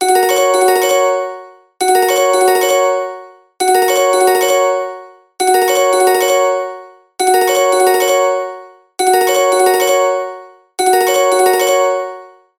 Kategorien Wecktöne